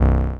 noise4.ogg